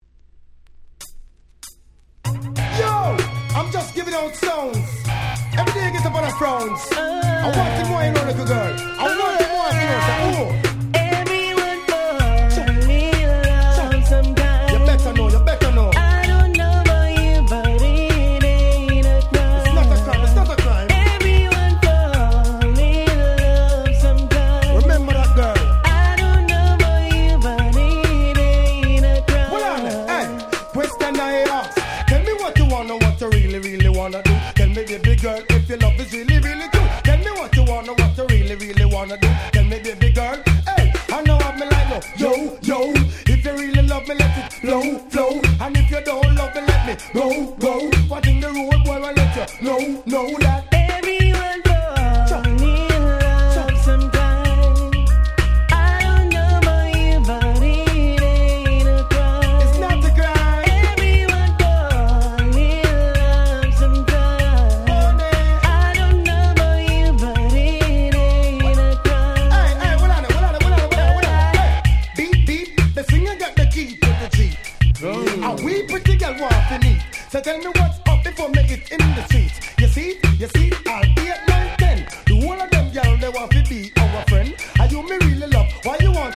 Nice Dancehall Reggaeコンピレーション！！